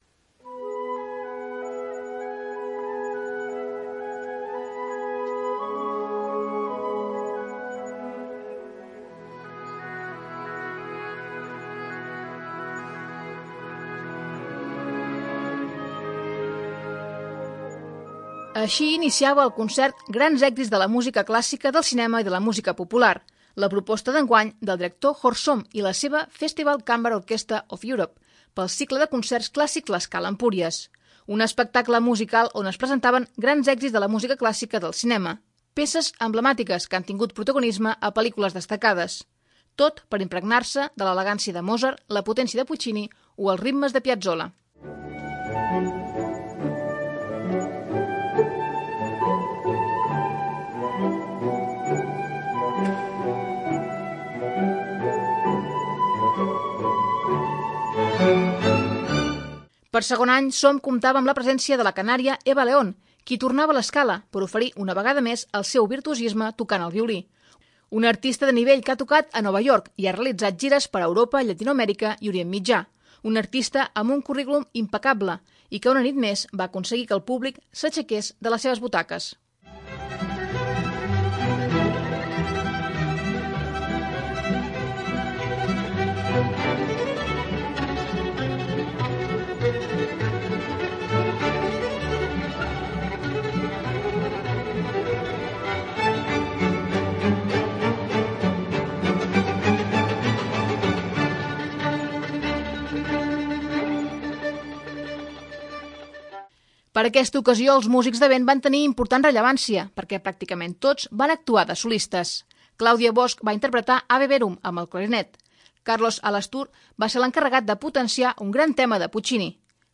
Un espectacle musical on es presentaven grans èxits de la música clàssica del cinema. Peces emblemàtiques que han tingut protagonisme a films destacats.